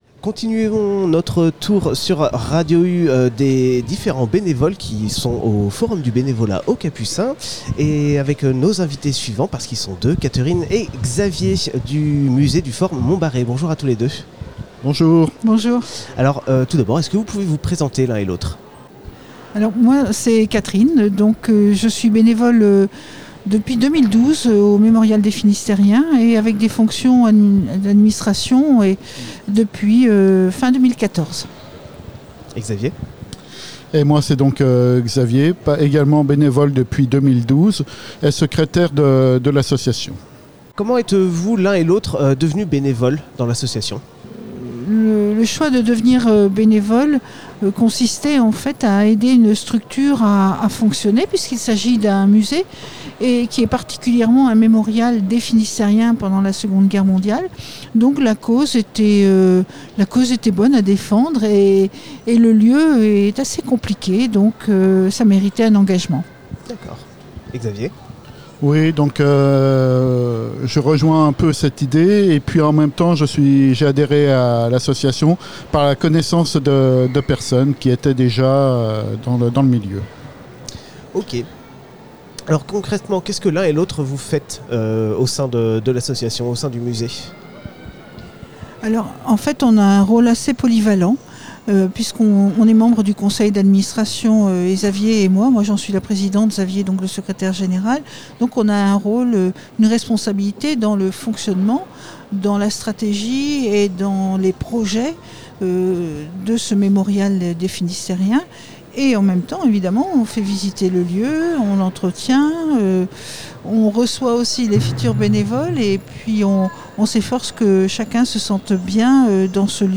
Samedi 1er Octobre 2022 se tenait aux Capucins les Rendez-Vous du Monde Associatif organisé par Brest’Assos.
Radio U était présente pour donner la parole aux différents acteurs et actrices du monde associatif sur Brest.
Nous recevions à notre micro des représentant·e·s salarié·e·s ou bénévoles des structures suivantes :